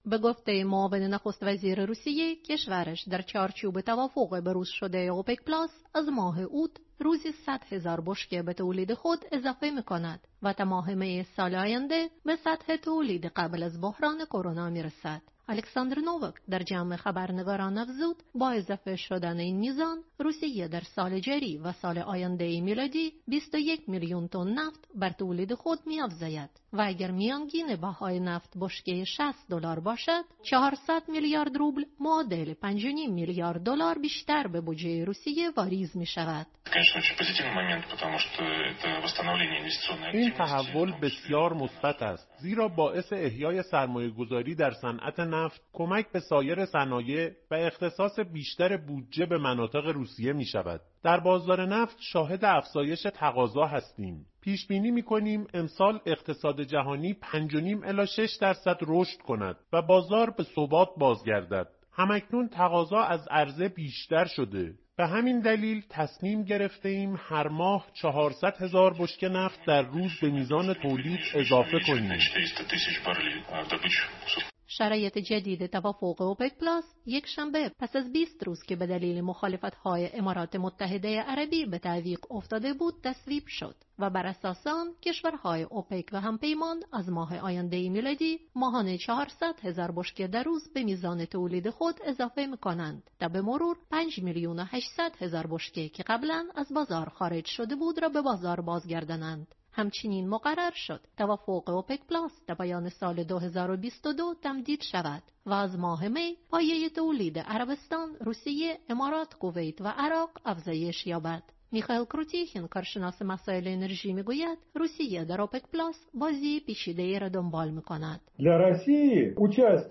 وزیر نفت ایران می‌گوید اوپک‌پلاس نمی‌تواند بازگشت ایران به بازار را نادیده بگیرد. گزارشی